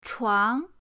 chuang2.mp3